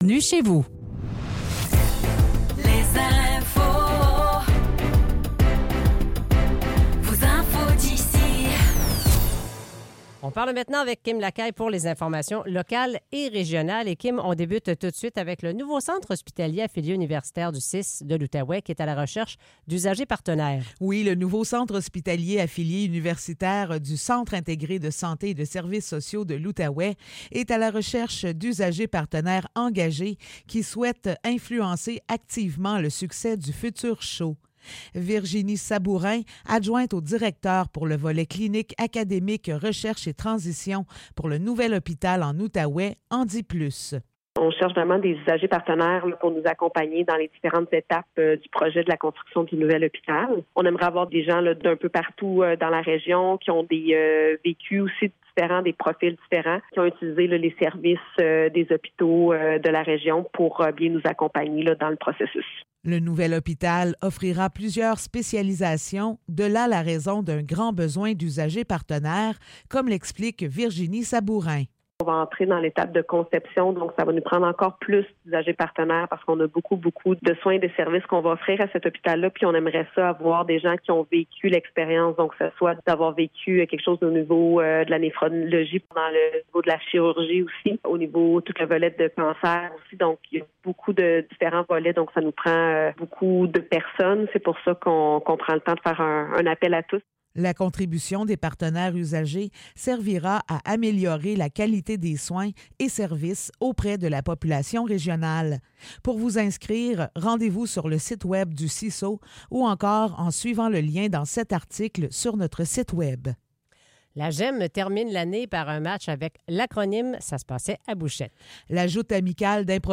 Nouvelles locales - 28 mai 2024 - 8 h